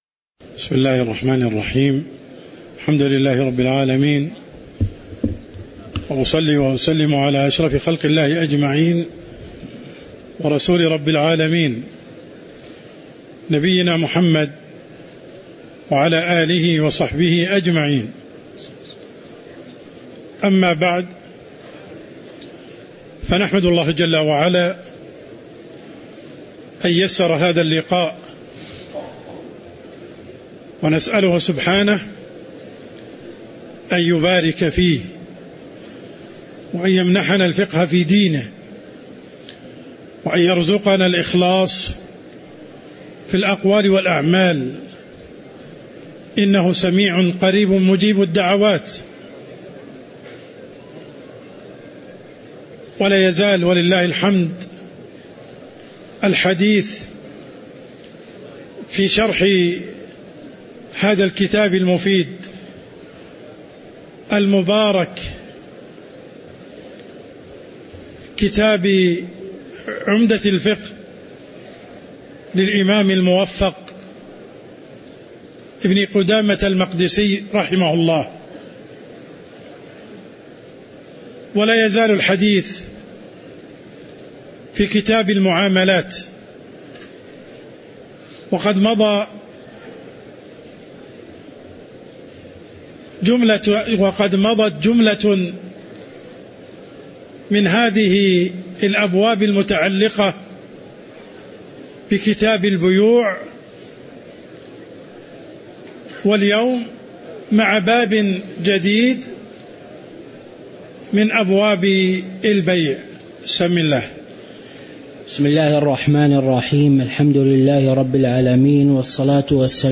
تاريخ النشر ١٨ ربيع الأول ١٤٤٤ هـ المكان: المسجد النبوي الشيخ: عبدالرحمن السند عبدالرحمن السند قوله: باب الهبة وهي تمليك المال في الحياة بغير عوض (02)